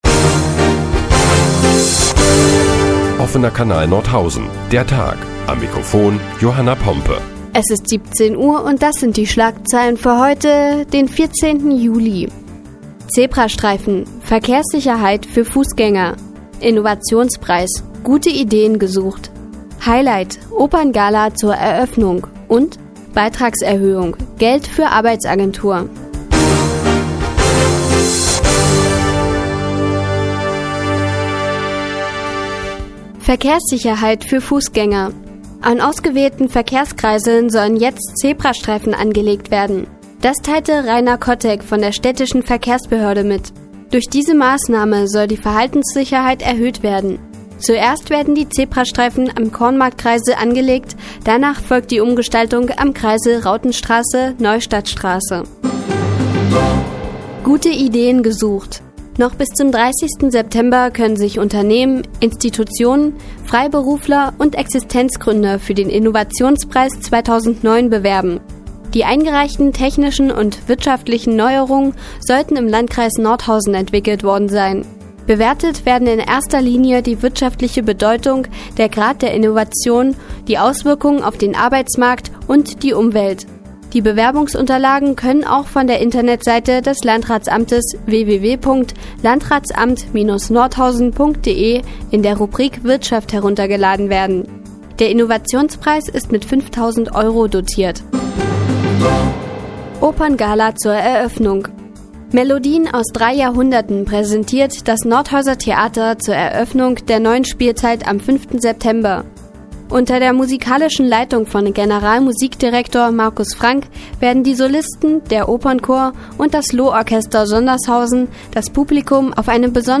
Die tägliche Nachrichtensendung des OKN ist nun auch in der nnz zu hören. Heute geht es unter anderem um mehr Verkehrssicherheit für Fußgänger und gute Ideen für den Innovationspreis.